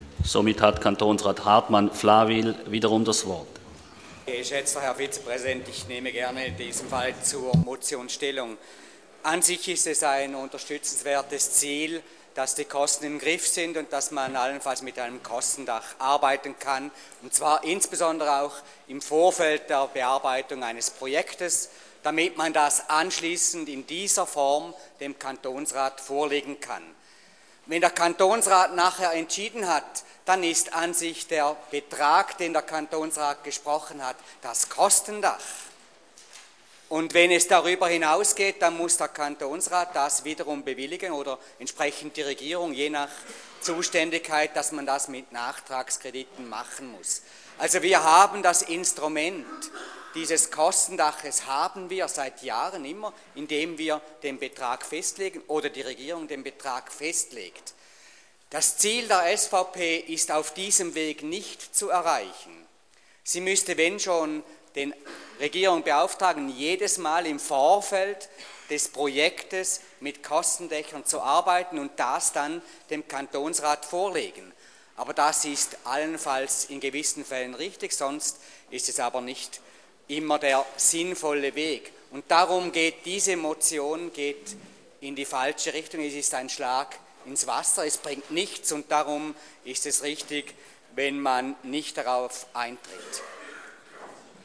26.2.2013Wortmeldung
Session des Kantonsrates vom 25. bis 27. Februar 2013